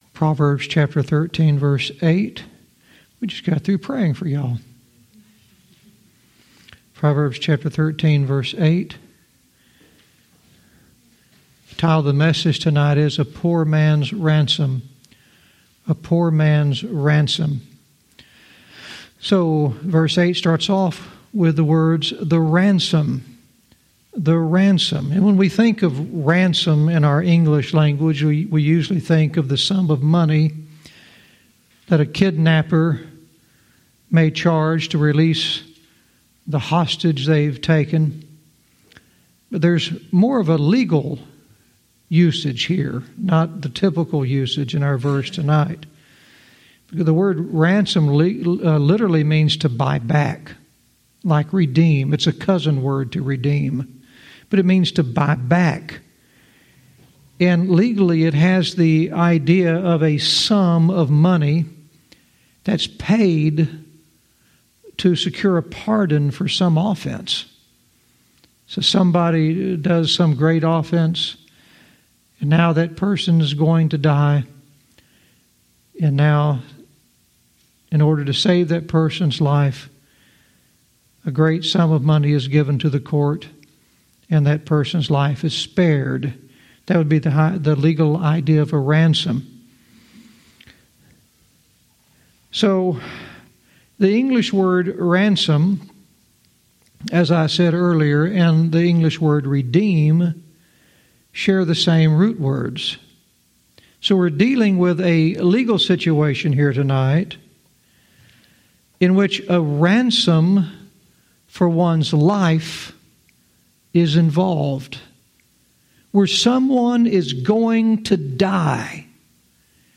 Verse by verse teaching - Proverbs 13:8 "A Poor Man's Ransom"